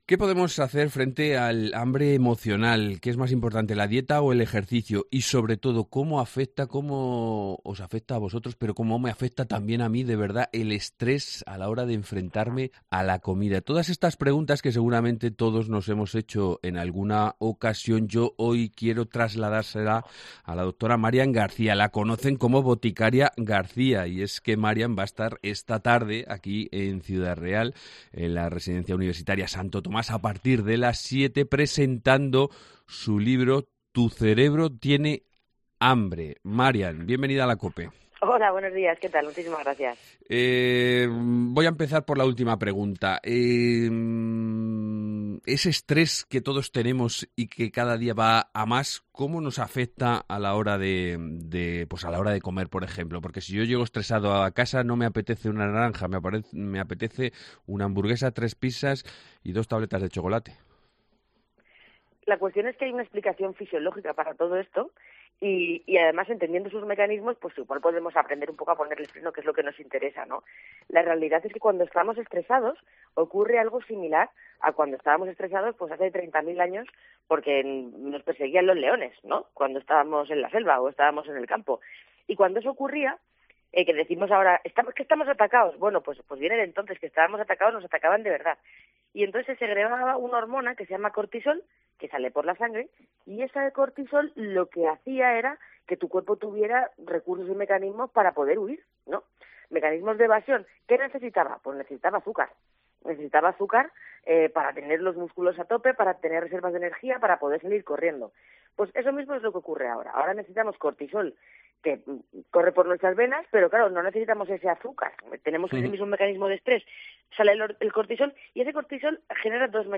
Entrevista con Boticaria García